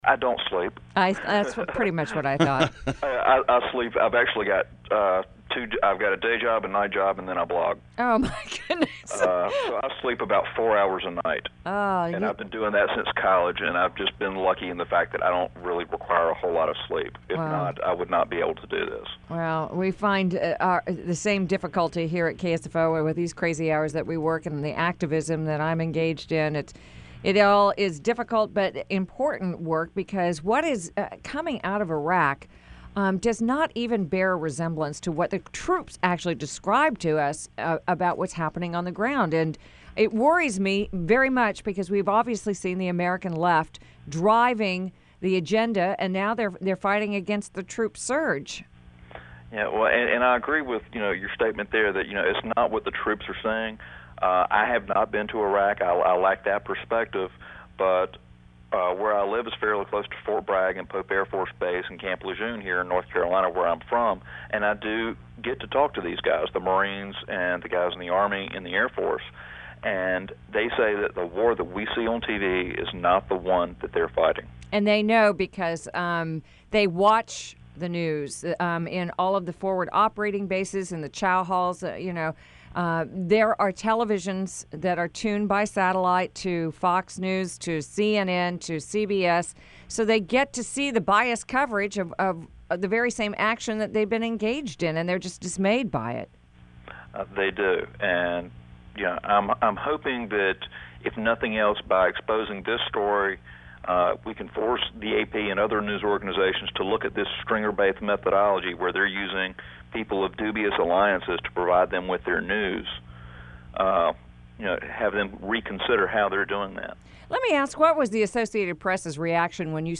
KSFO 560 AM